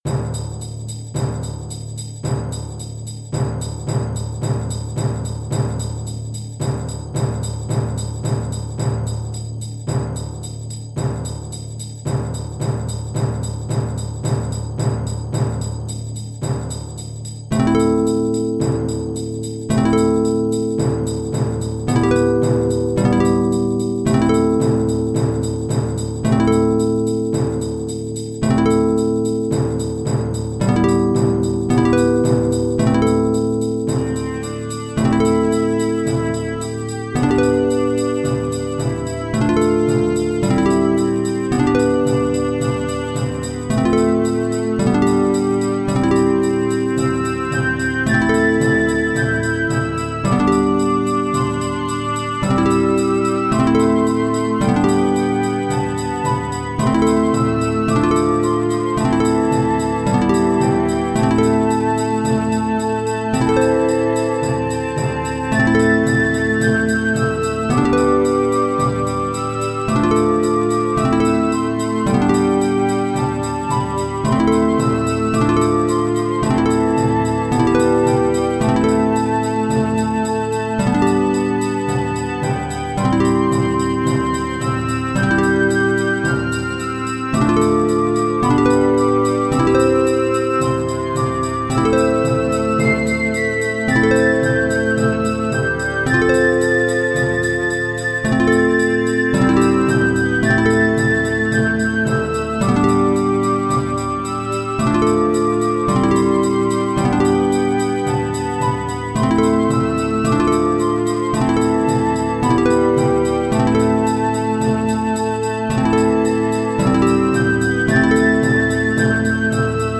Frigga’s Tale - Chamber Music
Mysterious and enchanting composition inspired by Iceland’s myth and folklore, using traditional Icelandic instruments and rhythm.
Instrumentation Piccolo, Viola, Bass Drum, Tambourine, and Harp Duration 3:49 Tempo = 55 Year Composed 2021 Listen View Excerpt